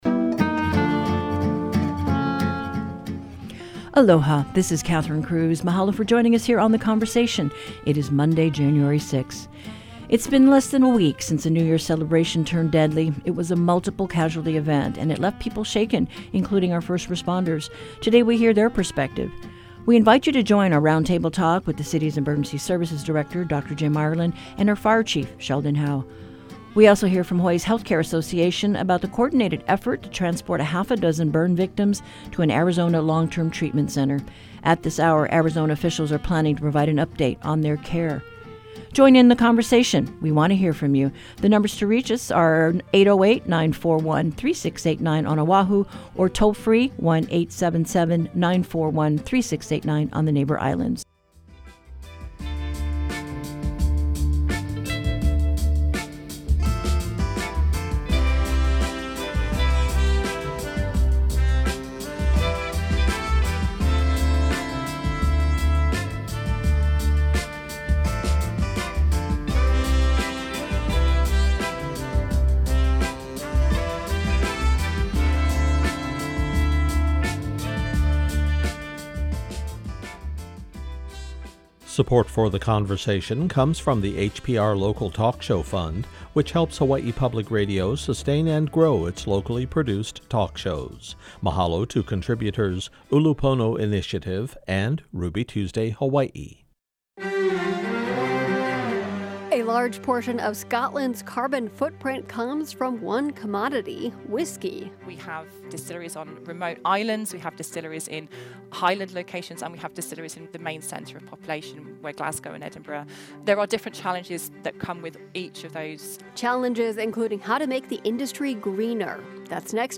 Whether you live in our state or far from our shores, you’ll know what’s happening in Hawaiʻi with HPR's daily hour of locally focused discussions of public affairs, ideas, culture and the arts. Guests from across the islands and around the world provide perspectives on life in Hawaiʻi — and issues that have not yet reached Hawaiʻi.